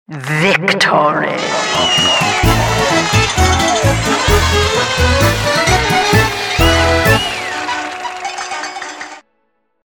victory themes.